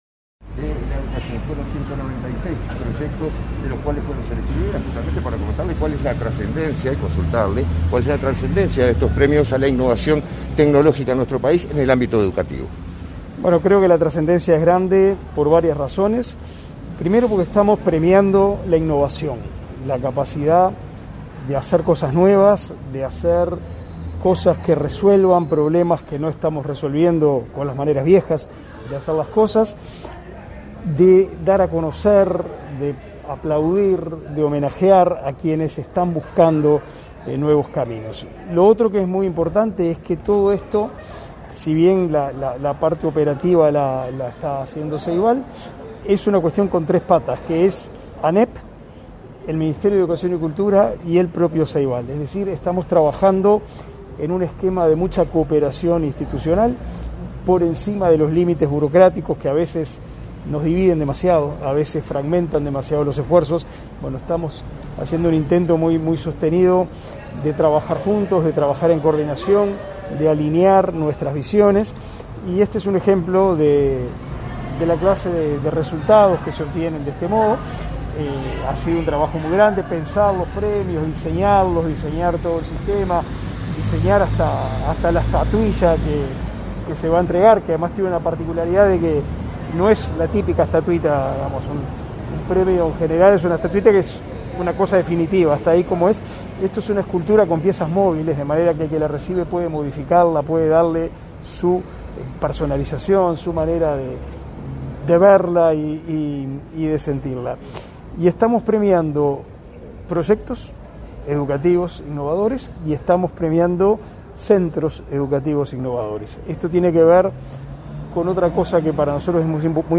Declaraciones a la prensa del ministro de Educación y Cultura, Pablo da Silveira
Declaraciones a la prensa del ministro de Educación y Cultura, Pablo da Silveira 23/11/2021 Compartir Facebook X Copiar enlace WhatsApp LinkedIn En el marco de un nuevo Enlace 360 en el teatro Solís, el Ministerio de Educación y Cultura, la Administración Nacional de Educación Pública y el Plan Ceibal otorgaron los Premios NODO a la Innovación Pedagógica de Uruguay, este martes 23 de noviembre. Tras el evento, Da Silveira efectuó declaraciones a la prensa.